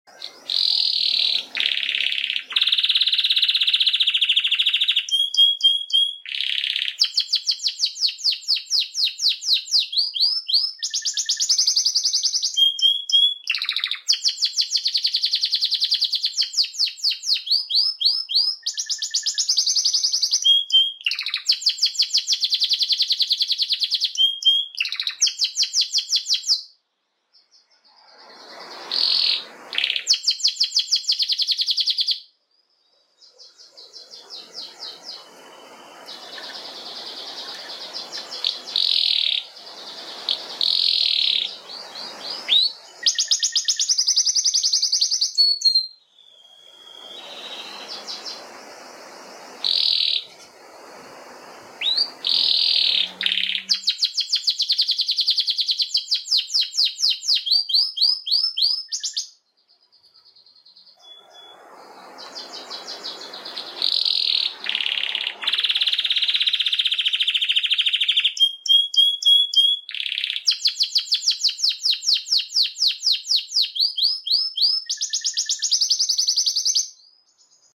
Canário Belga Soltando o Canto!!!